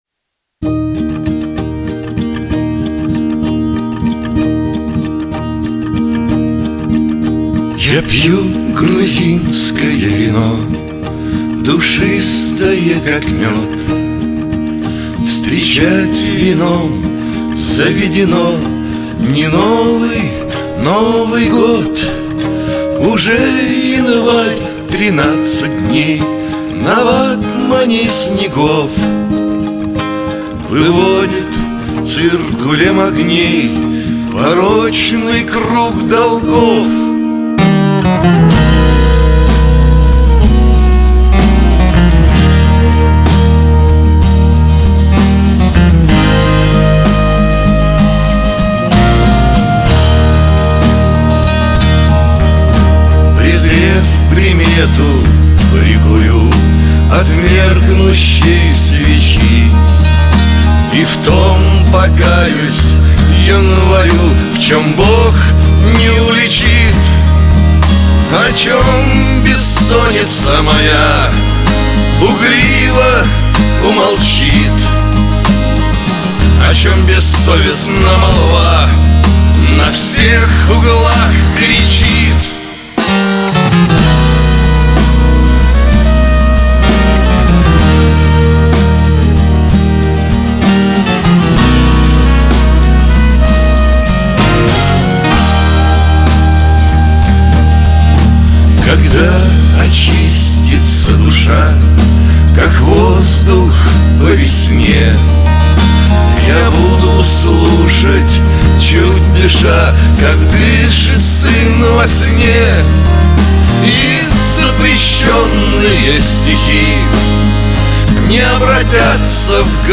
Жанр: Авторская песня